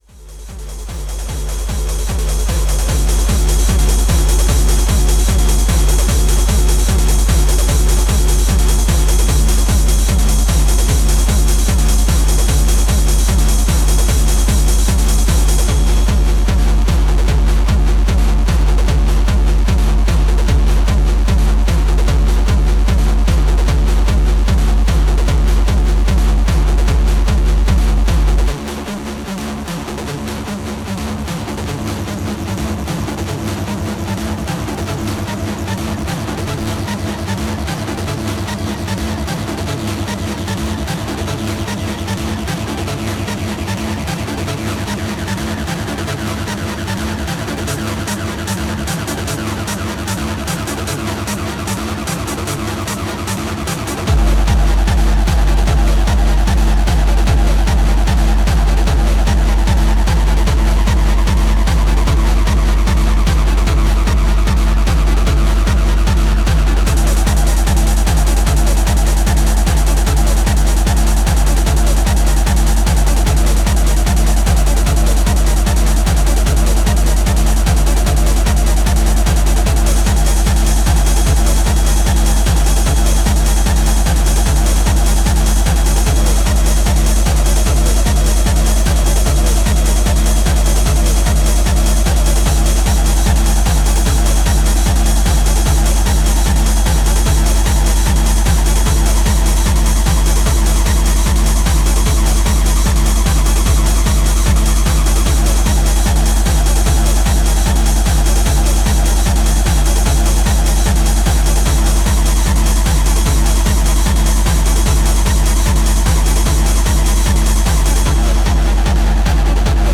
Hardtek/Tekno